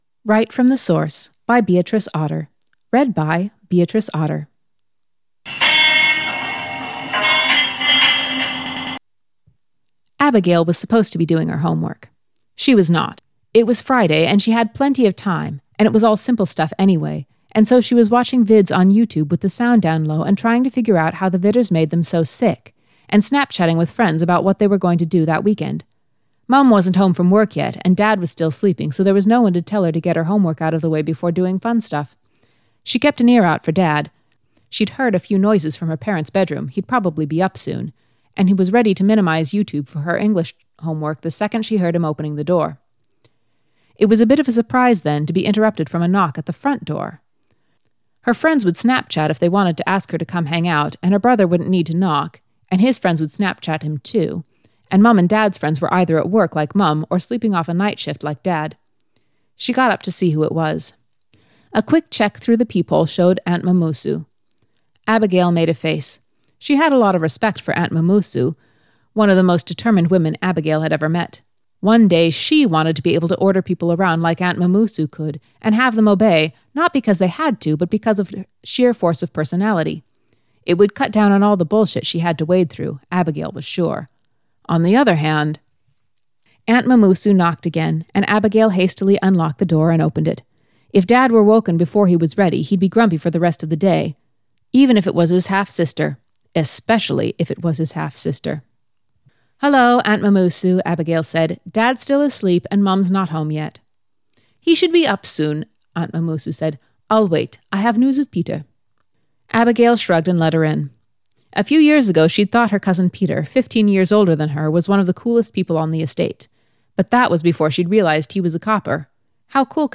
two voices